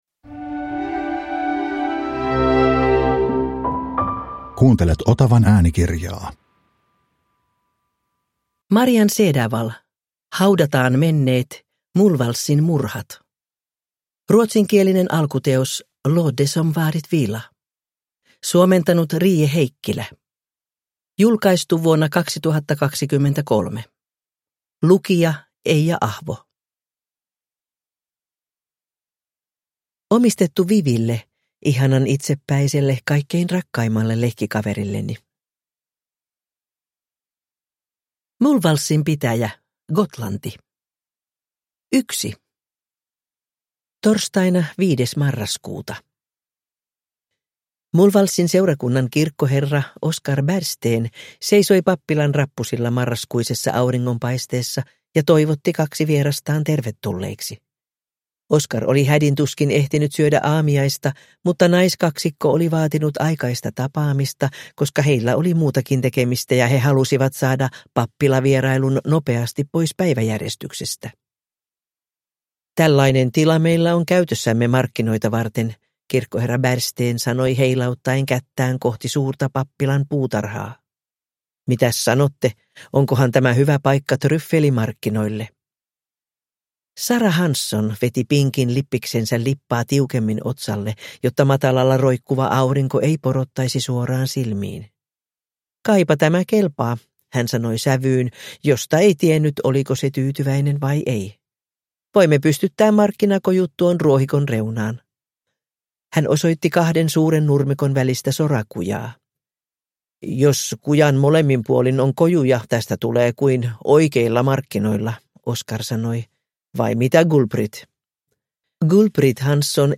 Haudataan menneet – Ljudbok – Laddas ner